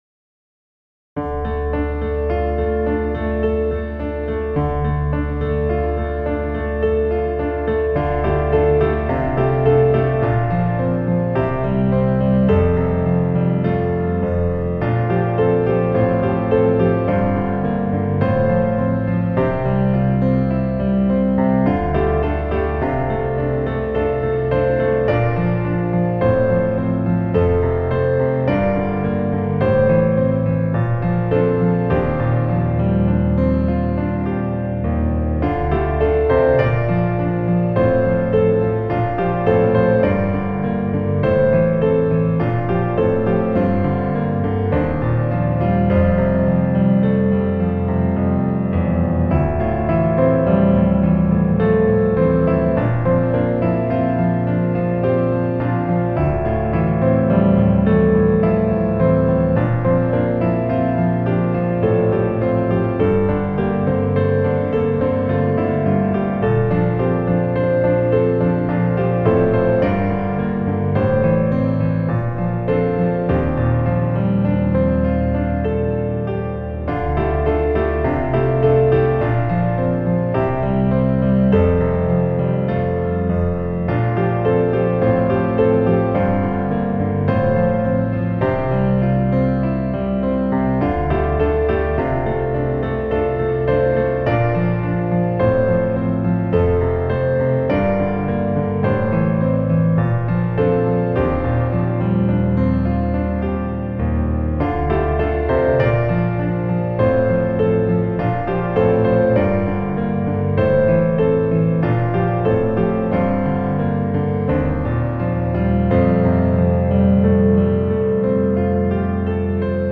Piano recording
• Piano Recording